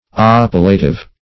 Search Result for " oppilative" : The Collaborative International Dictionary of English v.0.48: Oppilative \Op`pi*la*tive\, a. [Cf. F. opilatif.